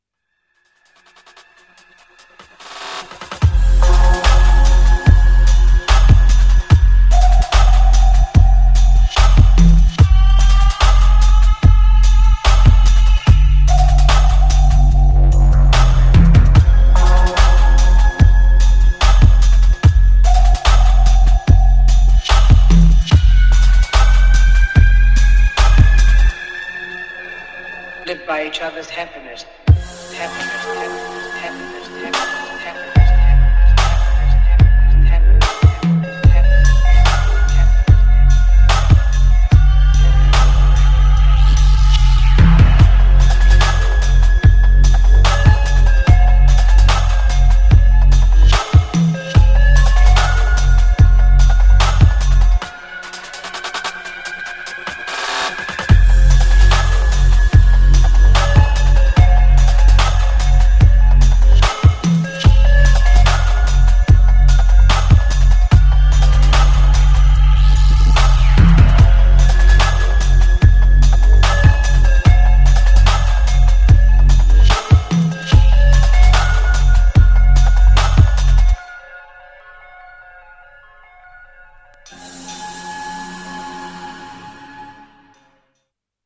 Styl: Dub/Dubstep, Drum'n'bass, Jungle/Ragga Jungle